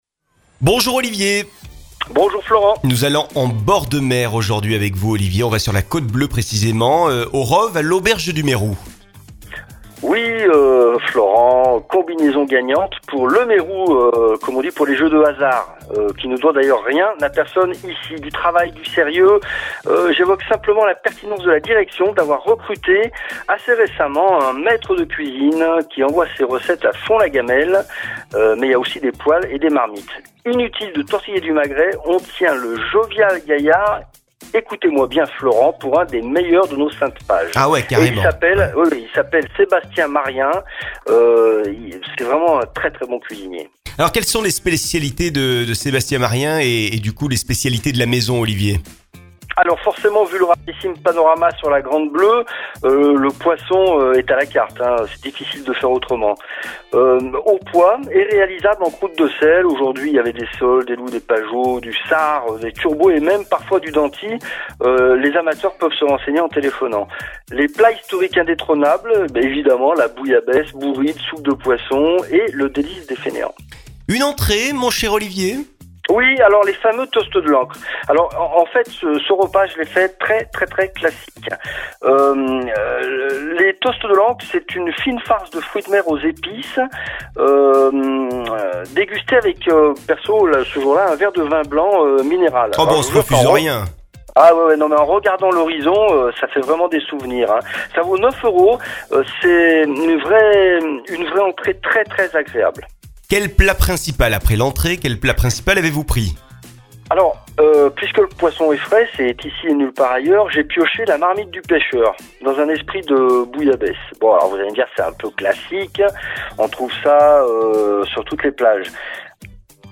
CHRONIQUES POUR RADIO